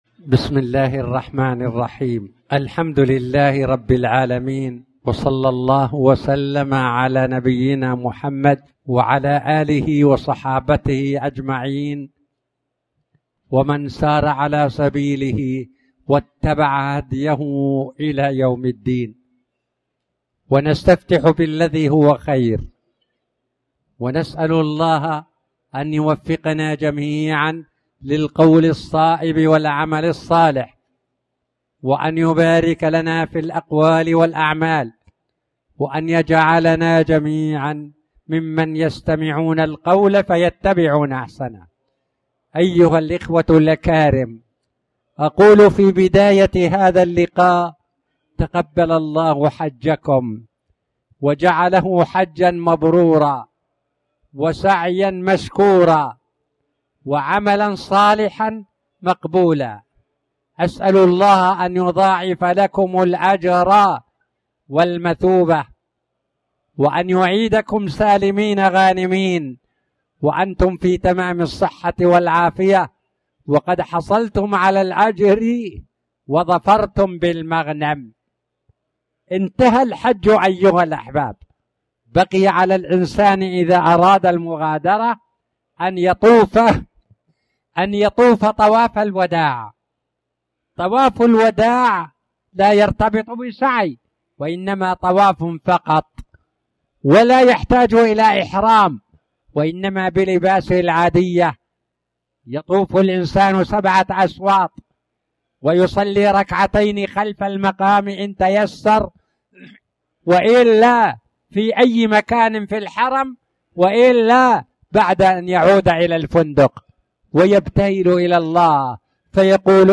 تاريخ النشر ٢٠ ذو الحجة ١٤٣٨ هـ المكان: المسجد الحرام الشيخ